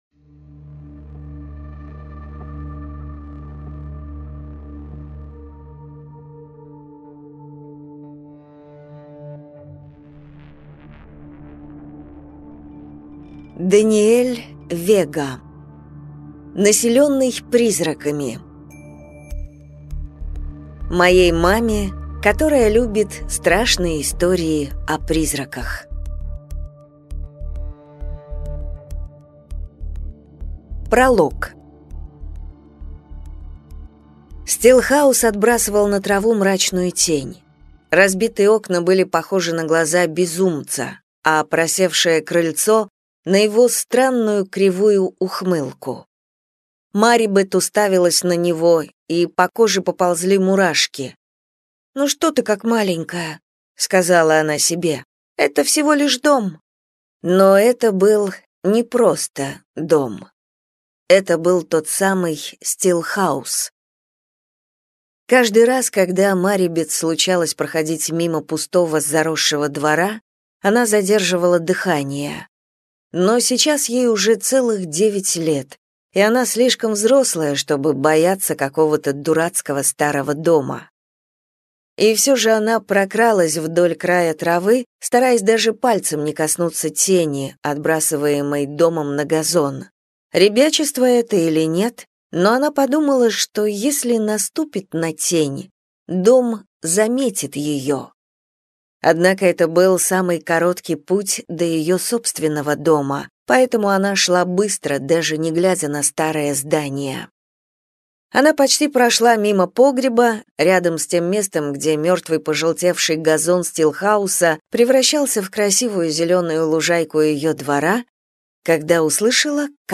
Аудиокнига Населенный призраками | Библиотека аудиокниг